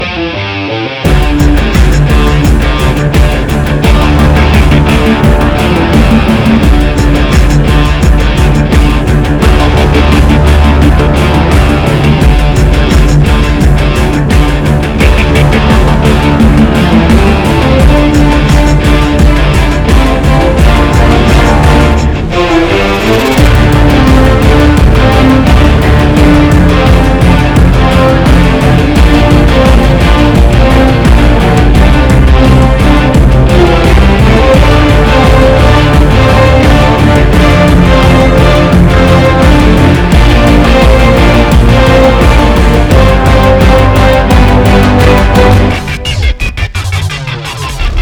• Качество: 320, Stereo
без слов
OST
Тема из кинофильма